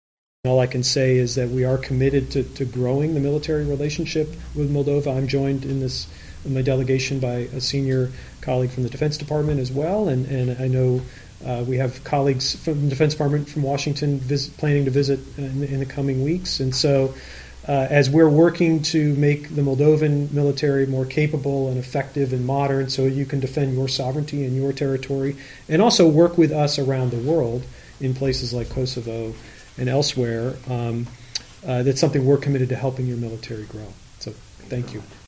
Declarația lui Derek Chollet, Consilier al Departamentului de Stat, despre relația militară cu R. Moldova